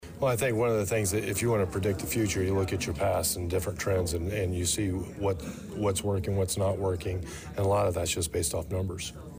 Also during the Public Services Committee meeting, Police Chief Christopher Yates talked about the improvement in the department’s “community policing” since the hiring of a Crime Intel Officer three years ago, and a Data Specialist within the last year.